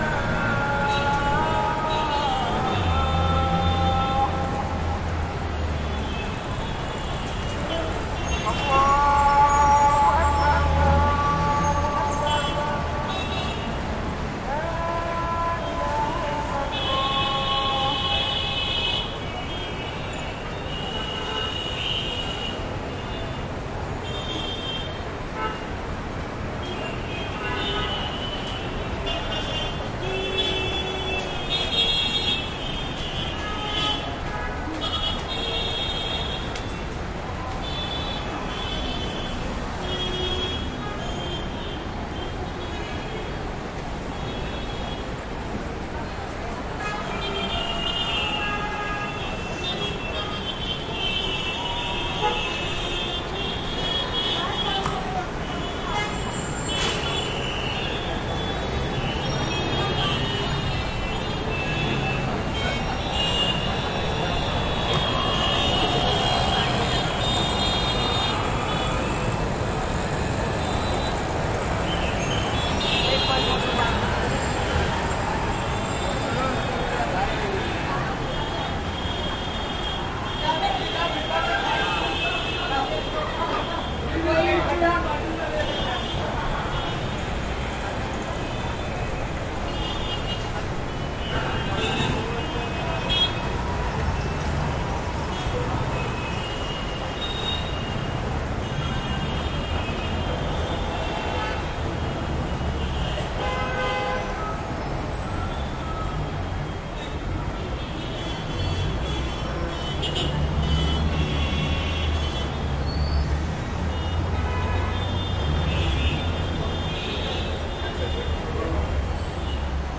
印度 " 交通拥堵不堪 恼怒的司机按喇叭 摩托车轻便摩托车 人力车 汽车 卡车 雾霾久久不散
描述：交通沉重的喉咙堵塞拥挤的司机喇叭鸣喇叭摩托车轻便摩托车人力车汽车卡车阴霾长印度.flac
Tag: 恼火 交通堵塞 交通 喇叭 人力车 轻便摩托车 车手 赛车 honks 卡车 摩托车 嘶哑的 长的 印度的